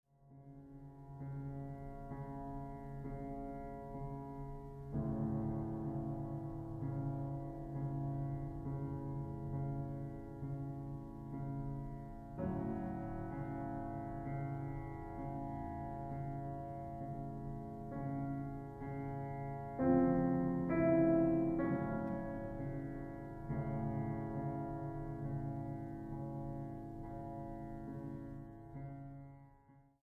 pianista
Música Mexicana para Piano